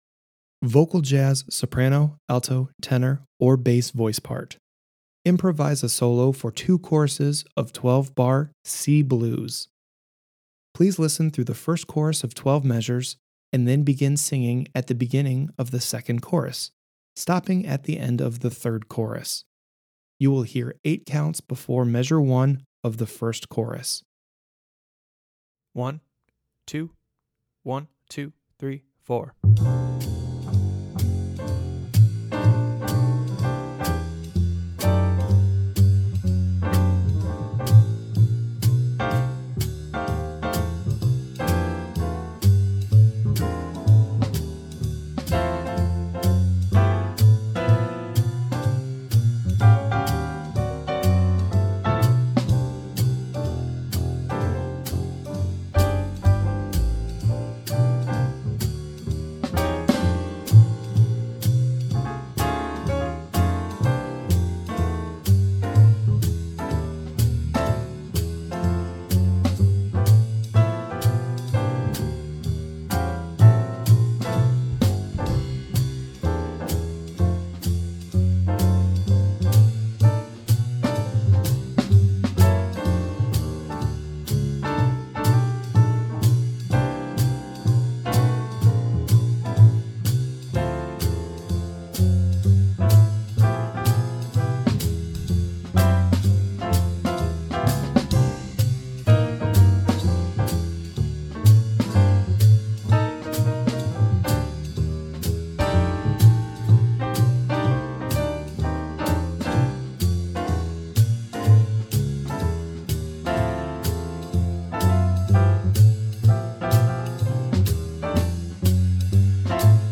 Vocal Jazz
Improvisation Track
Vocal Improv (1).mp3